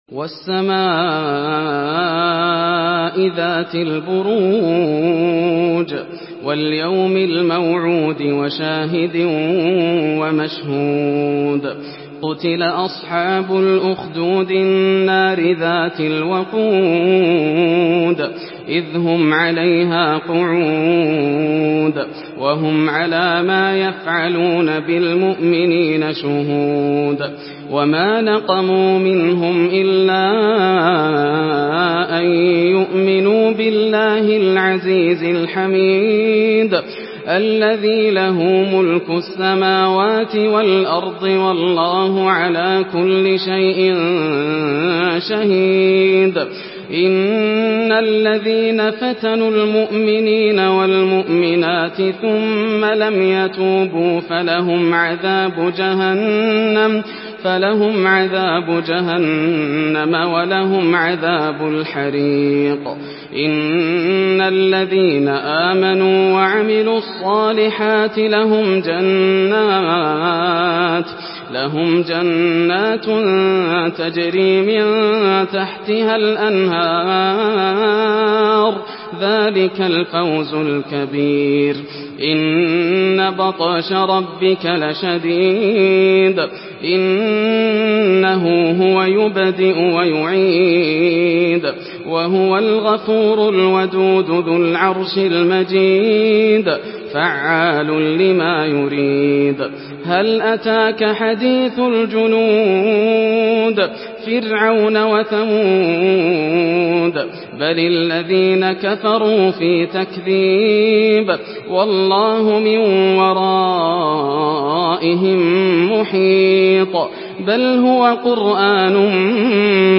Surah আল-বুরূজ MP3 in the Voice of Yasser Al Dosari in Hafs Narration
Listen and download the full recitation in MP3 format via direct and fast links in multiple qualities to your mobile phone.